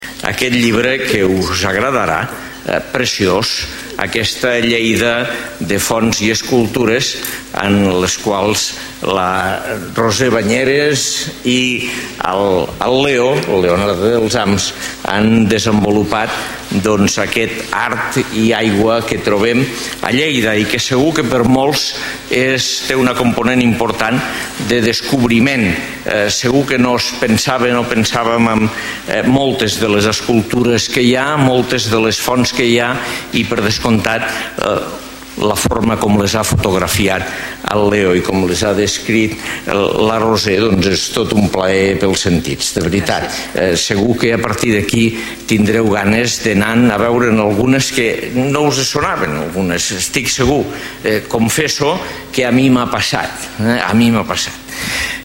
Tall de veu A. Ros .